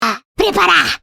A Brazilian Portuguese voice clip, likely from a character or viral moment.